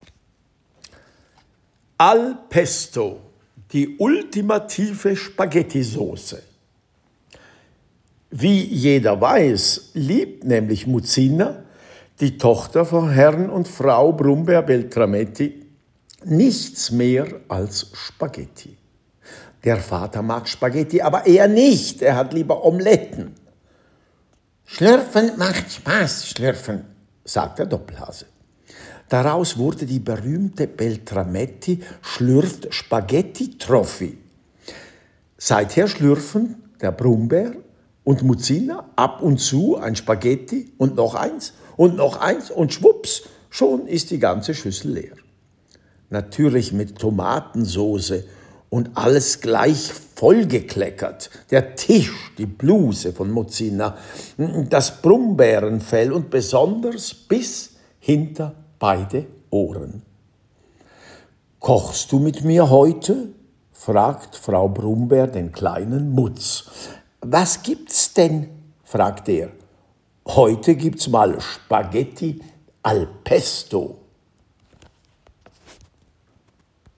Gleichzeitig sind die Rezepte und Geschichten auch als Hörgeschichten via QR-Code im Buch erhältlich; auf Schweizerdeutsch/Dialekt sowie auch auf Hochdeutsch – erzählt und gesprochen von Linard Bardill.
> Hörbeispiel zum Rezept Nr. 2 «Al pesto» (Hochdeutsch) >>>